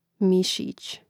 mìšīć mišić